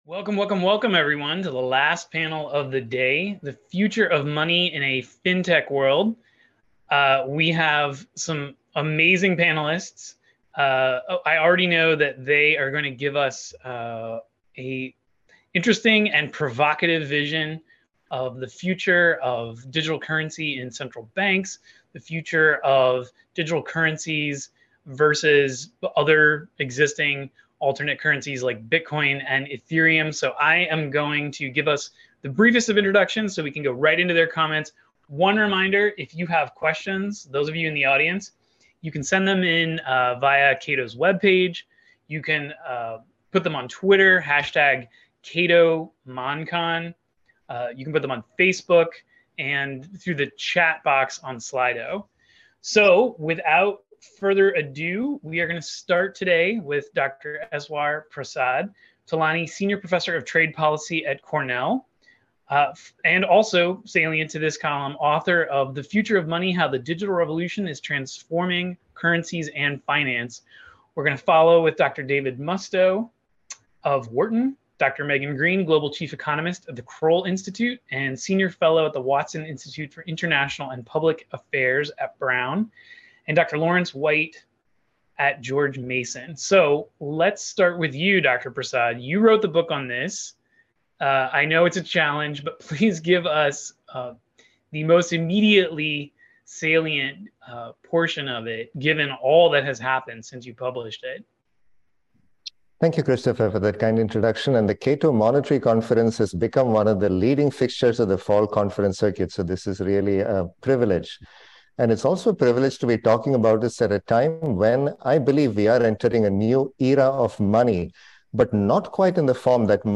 Panel 3: The Future of Money in a Fintech World and Closing Remarks — Cato Institute 40th Annual Monetary Conference